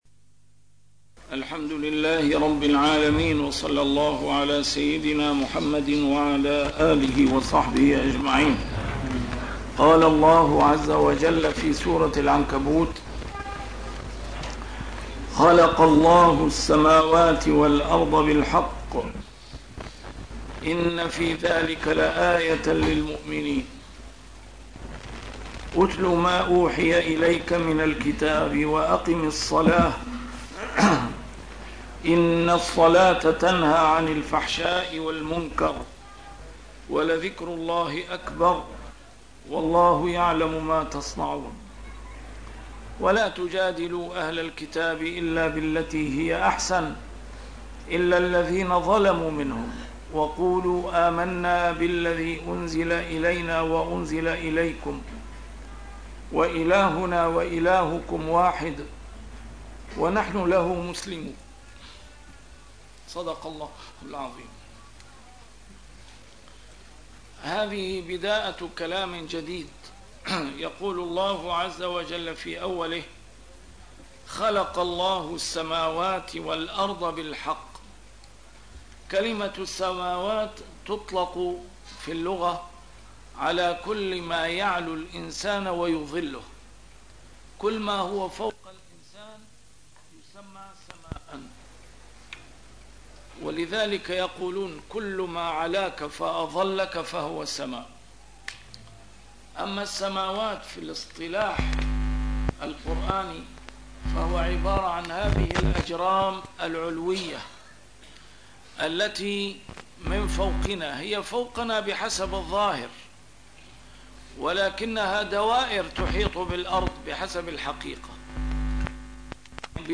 A MARTYR SCHOLAR: IMAM MUHAMMAD SAEED RAMADAN AL-BOUTI - الدروس العلمية - تفسير القرآن الكريم - تسجيل قديم - الدرس 304: العنكبوت 44-45